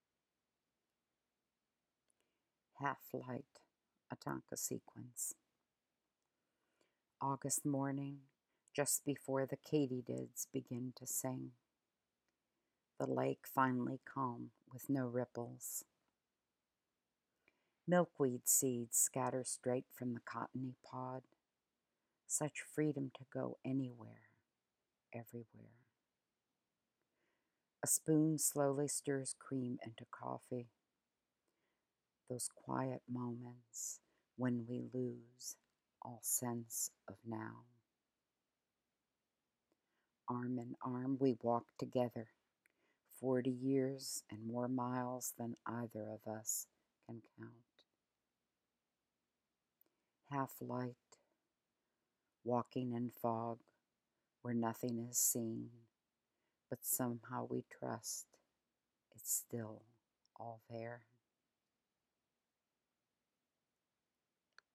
Click on the link below if you care to hear me read it.